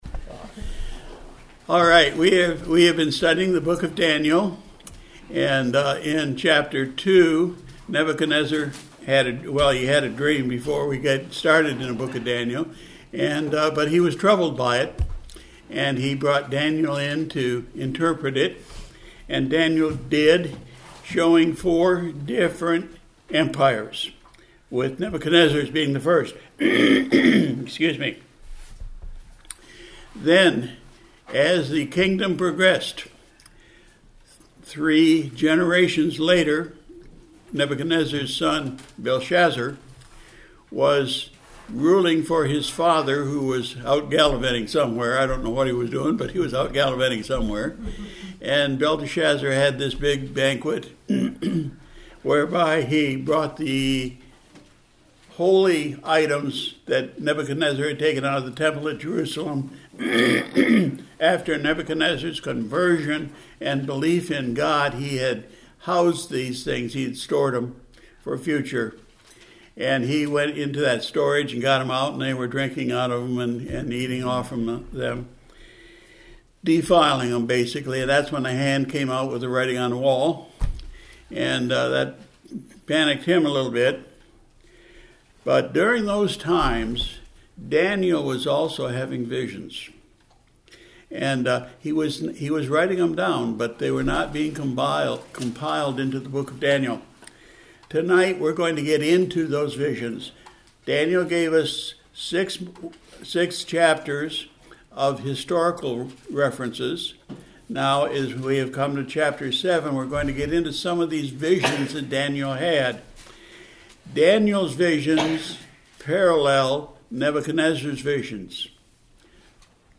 Sunday, March 16, 2025 – PM Service – Daniel 7 & 8